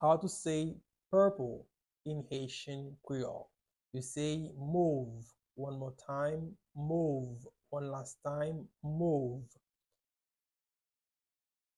Pronunciation:
14.How-to-say-Purple-in-Haitian-Creole-–-mov-with-Pronunciation.mp3